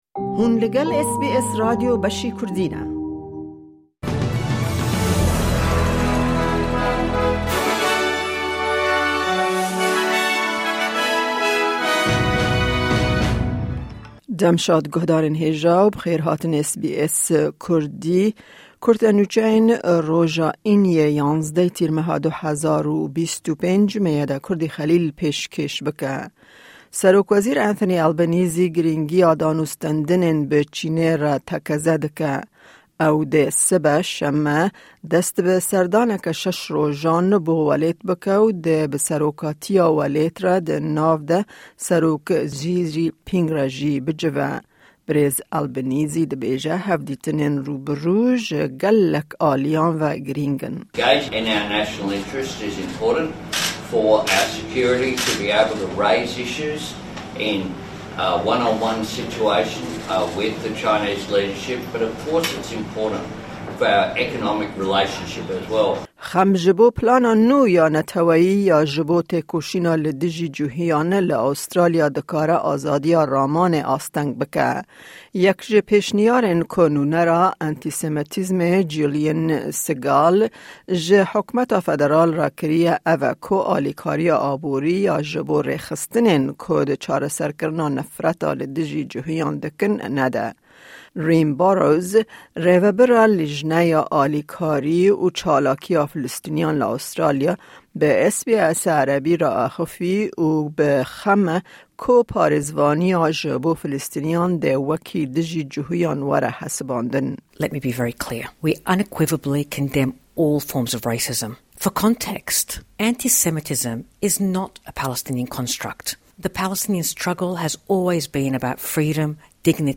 Kurte Nûçeyên roja Înî 11î Tîrmeha 2025